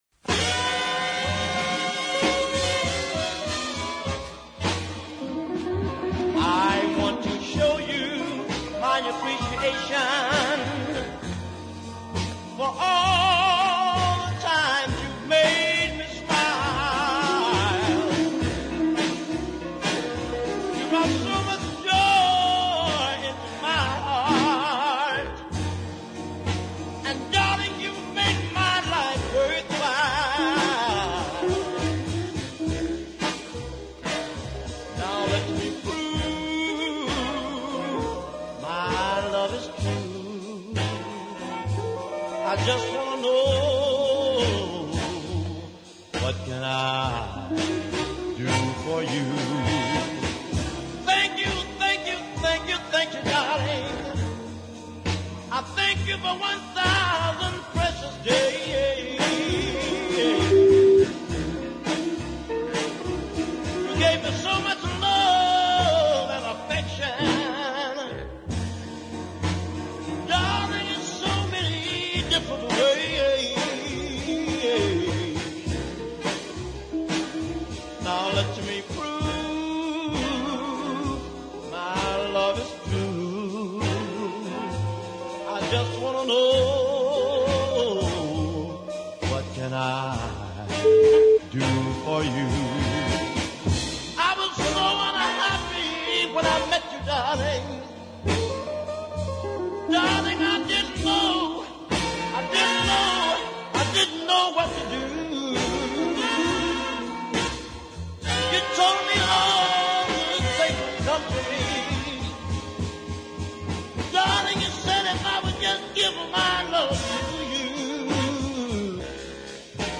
brassy
on which he really sings hard